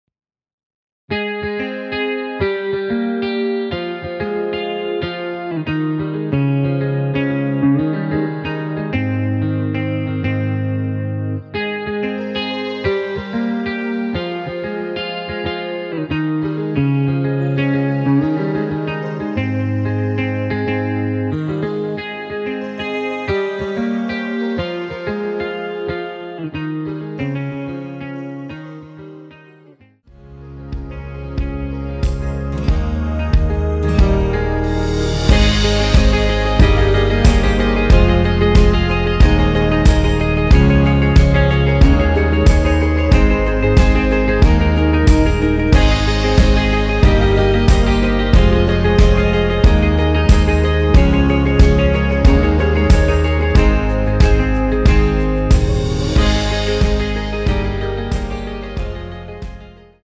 원키에서(-1)내린 멜로디 포함된 MR입니다.(미리듣기 확인)
Ab
앞부분30초, 뒷부분30초씩 편집해서 올려 드리고 있습니다.
중간에 음이 끈어지고 다시 나오는 이유는